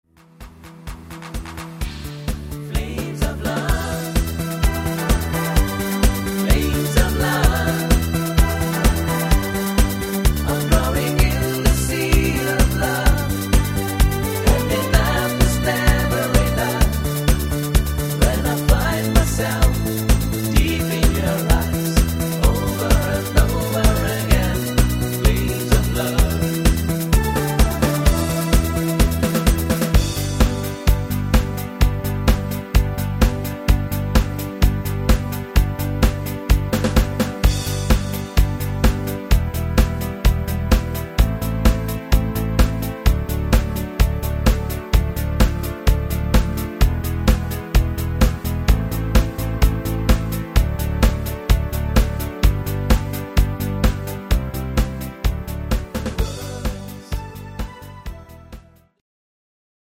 (gut zu singende Tonarten)
Rhythmus  Discofox
Art  Deutsch, Kurzmedley, Oldies